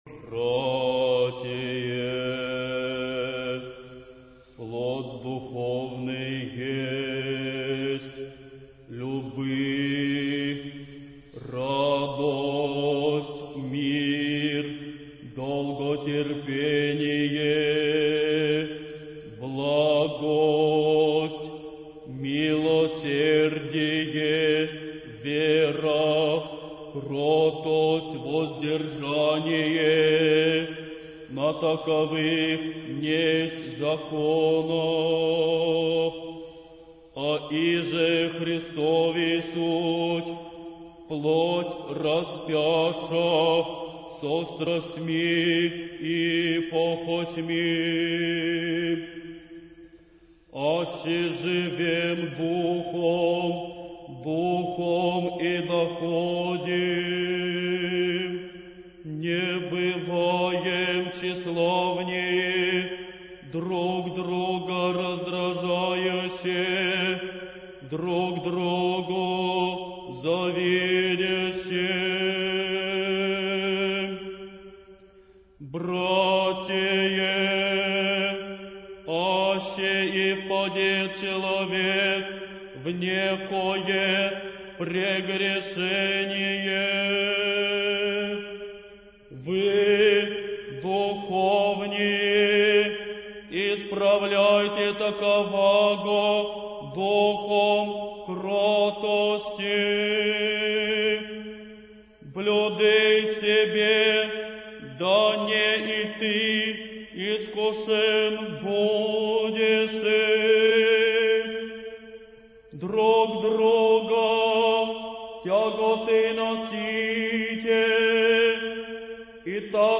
Духовная музыка
Чтение Апостола